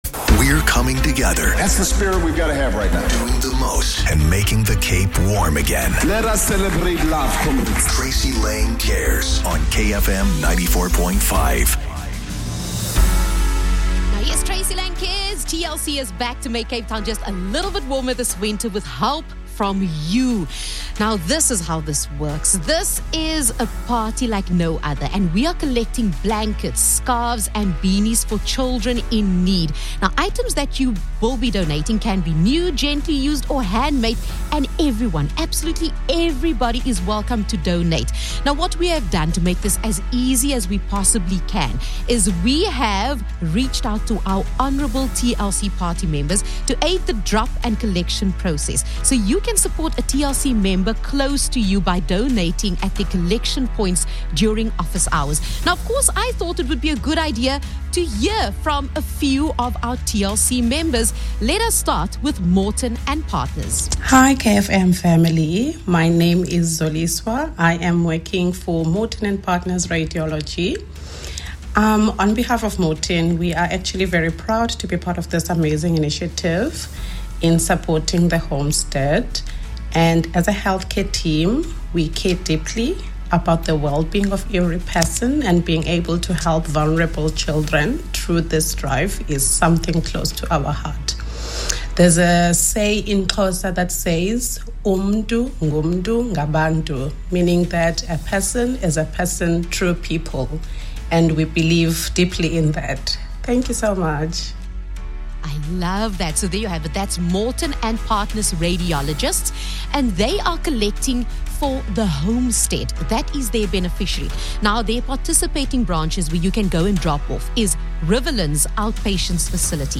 🎧 Missed the radio segment?